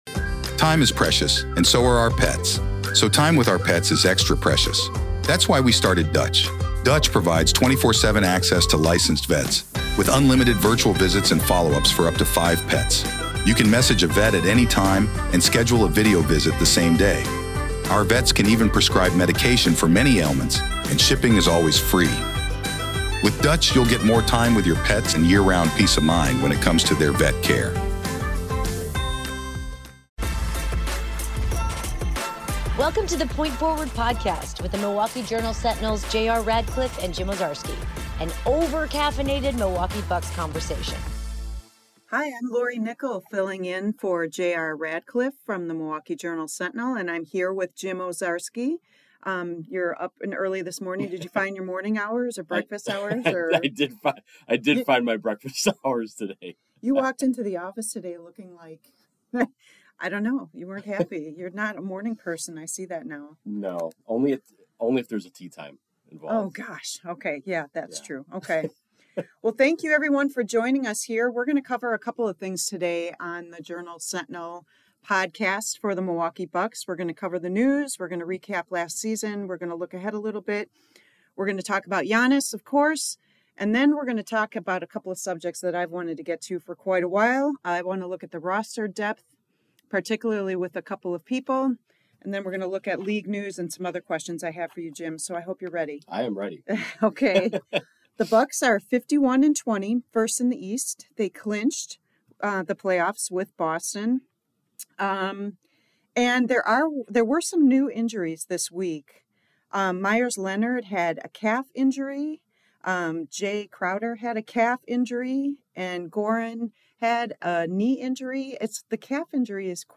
Music intro